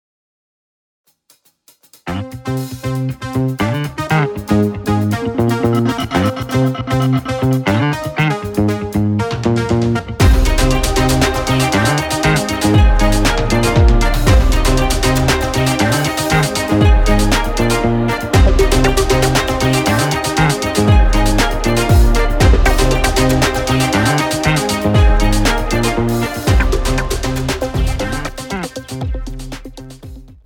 • Type : Instrumental
• Bpm : Allegretto
• Genre : Hiphop/ Rap / Techno